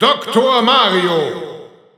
German Announcer announcing Dr. Mario.
Dr._Mario_German_Announcer_SSBU.wav